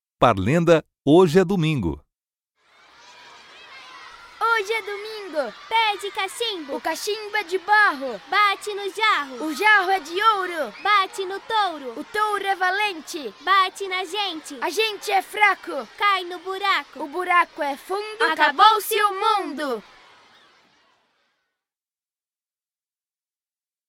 Parlenda "Hoje é domingo"